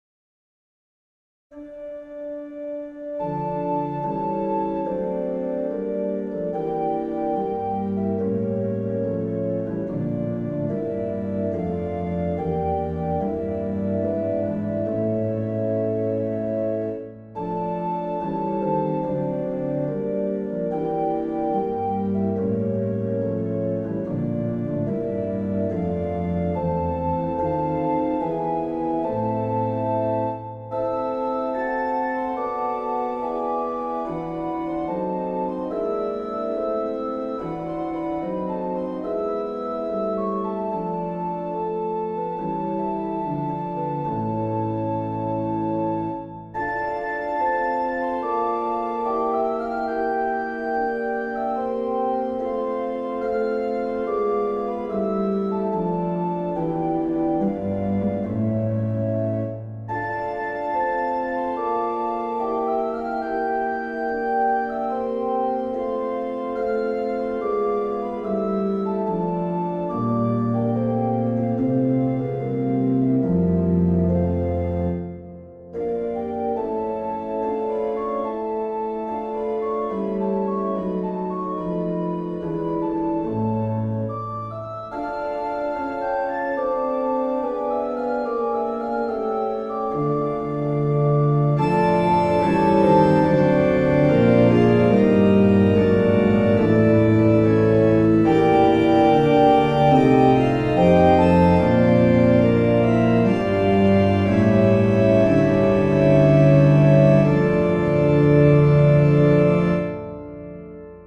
pour orgue ou harmonium